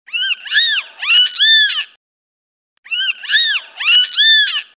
• Качество: 320, Stereo
на смс